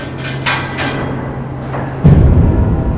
Walzwerk, Walzenstuhl
Alle Kanaldaten sind Hardware-gefiltert mit zwei RC-Tiefpässen 300 Hz.
Messungen an Position Z, zentrisch zum Getriebe unten
Entwicklung des Wellenfeldes am Getriebe unten, Messposition Z mit 5000 Bildern pro Sekunde, Integrationszeit 0.4 ms. Das Movie zeigt sowohl wischende als auch stehende Emissionen.